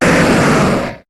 Cri de Kangourex dans Pokémon HOME.